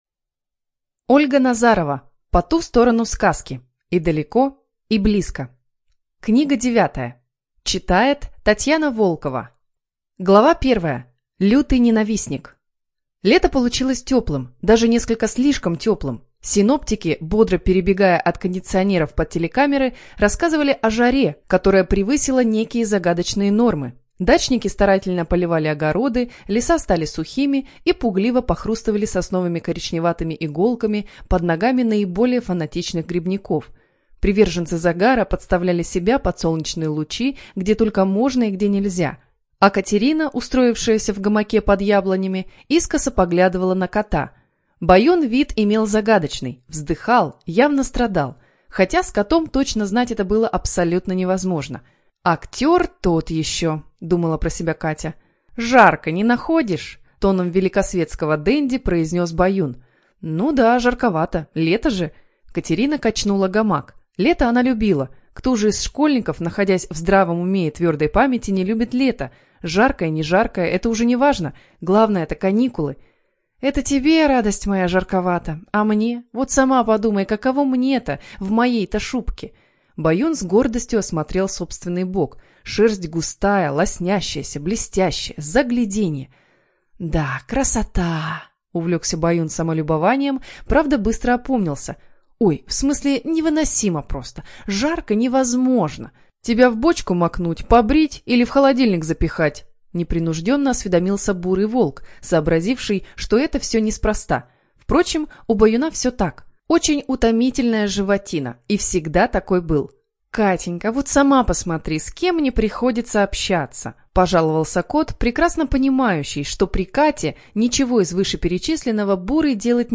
ЖанрФантастика, Детская литература